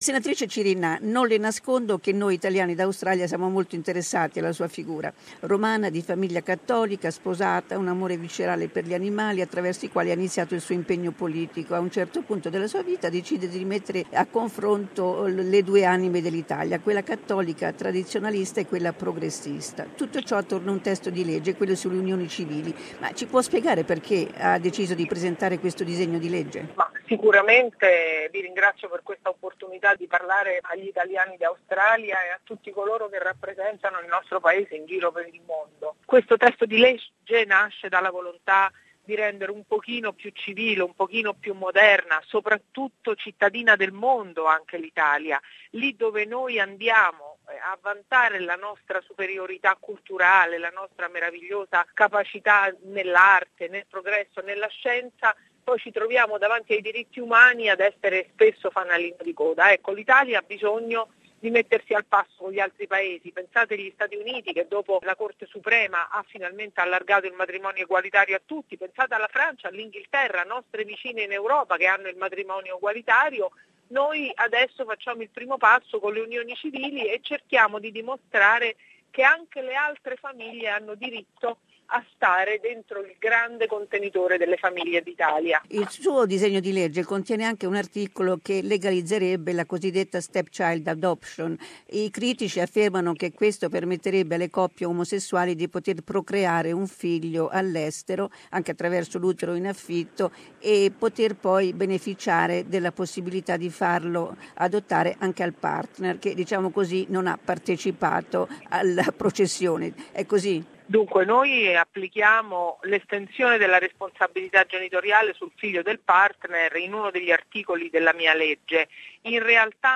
We interviewed the sponsor of the bill, Senator Monica Cirinnà.